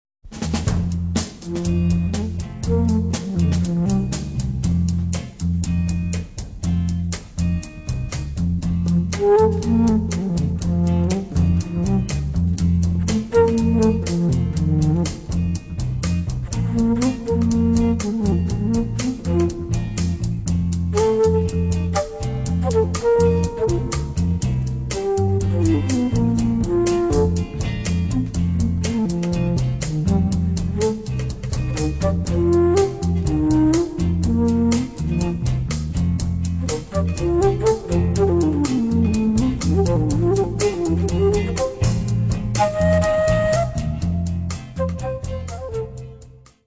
Poliziesco - Police Film - Polizeifilm relaxed medium instr.